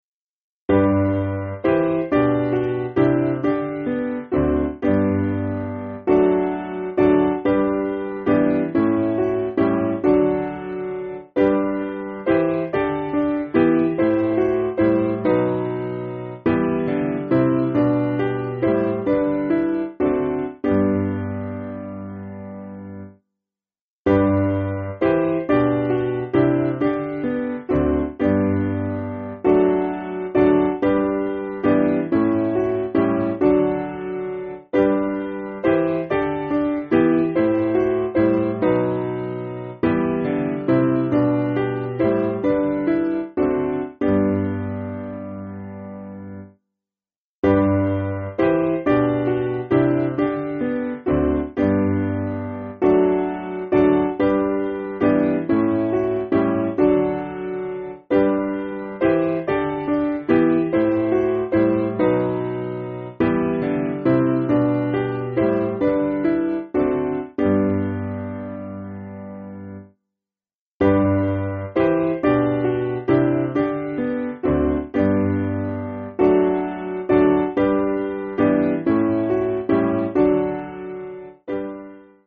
Simple Piano
(CM)   4/G